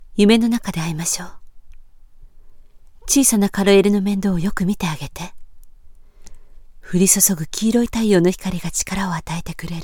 Commerciale, Polyvalente, Fiable, Corporative, Jeune